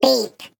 Sfx_tool_spypenguin_vo_horn_03.ogg